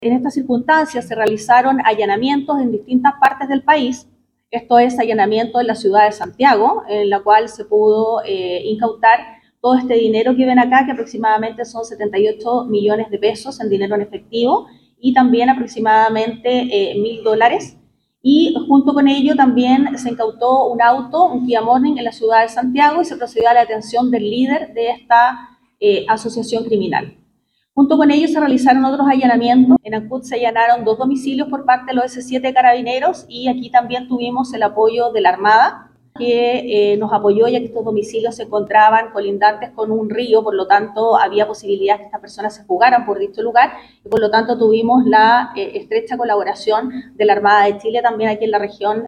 La fiscal De Miguel puntualizó que se llevaron a efecto una serie de allanamientos en distintos puntos del país, incluida la provincia de Chiloé, donde se focalizó el trabajo en dos domicilios de la comuna de Ancud, donde se detuvieron a tres personas, en colaboración con la Armada.
07-FISCAL-MARIA-ANGELICA-DE-MIGUEL-2.mp3